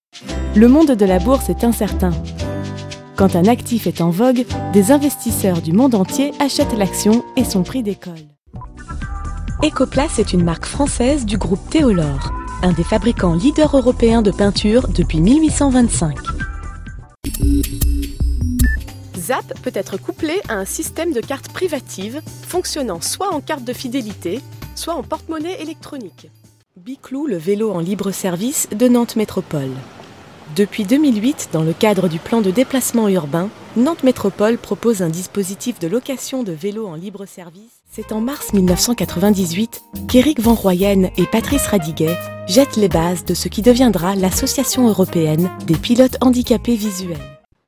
Sprechprobe: eLearning (Muttersprache):
Home studio, prompt delivery.